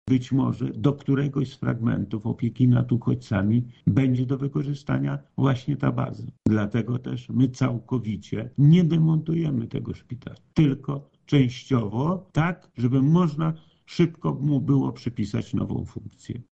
Jeszcze nie wiadomo, w jaki sposób przestrzeń zostanie zagospodarowana – mówi wojewoda lubelski Lech Sprawka.